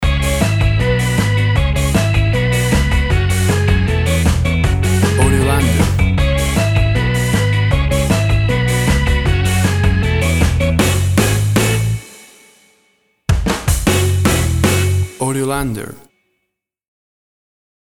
Classic reggae music with that skank bounce reggae feeling.
Tempo (BPM) 156